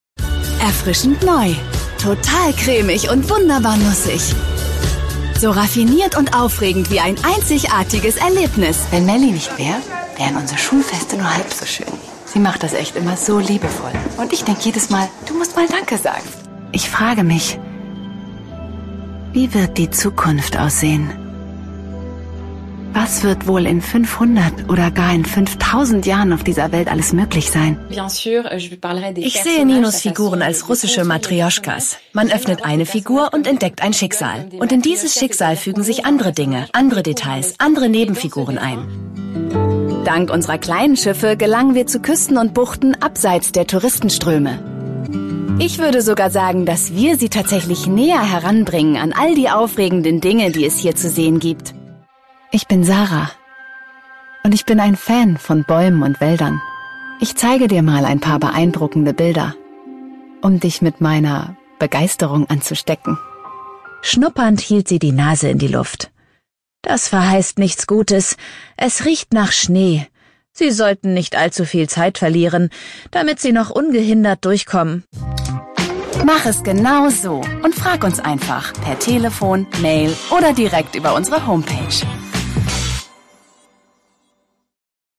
Sprecherin, Schauspielerin
Dialekte: Hamburgisch, Norddeutsch
Showreel ° Deutsch